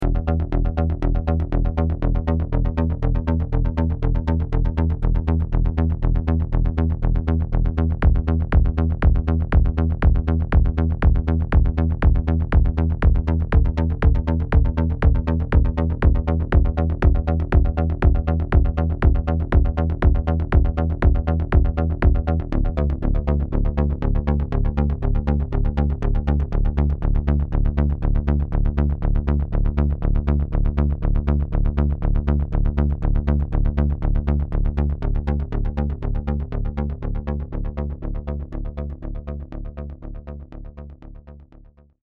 Here is a free bass sound from the Geometry package. The sound played by arpeggiator. The LFO slowly and gently changes the waveform. (The kick also from this pack.)